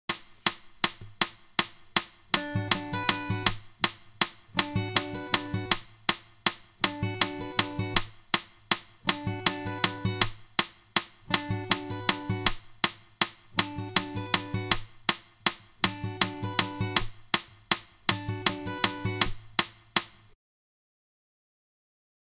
Finger Pick Hand
Full Speed mp3
Repeats 8X
7th position B minor triad.